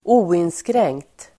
Ladda ner uttalet
oinskränkt adjektiv, unrestricted Uttal: [²'o:inskreng:kt] Böjningar: oinskränkt, oinskränkta Synonymer: obegränsad Definition: obegränsad, fullständig Exempel: ha oinskränkt makt (have absolute (unlimited) power)